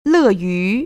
[lèyú] 러위  ▶